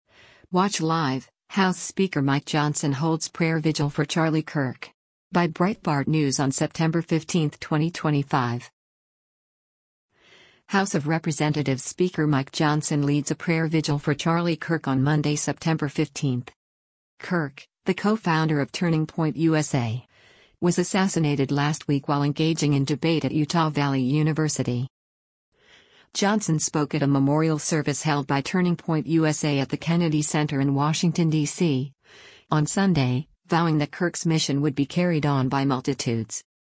House of Representatives Speaker Mike Johnson leads a prayer vigil for Charlie Kirk on Monday, September 15.